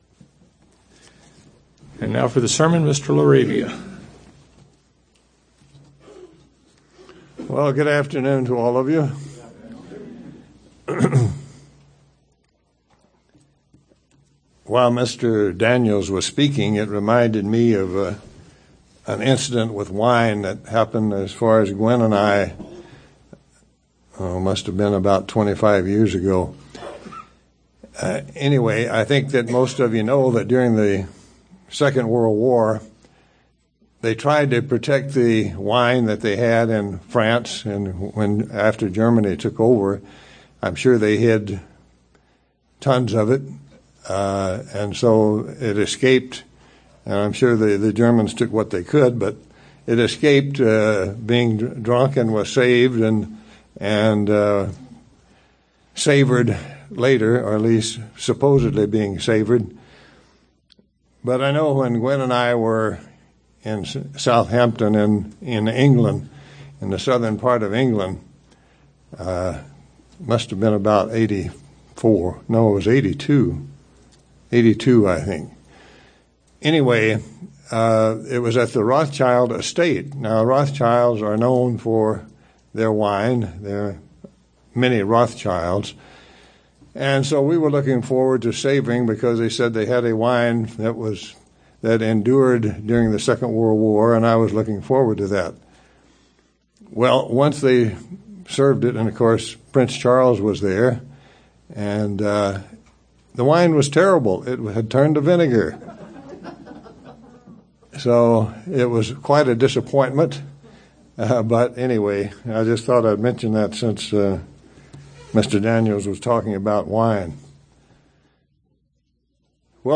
Given in Tucson, AZ El Paso, TX
UCG Sermon Studying the bible?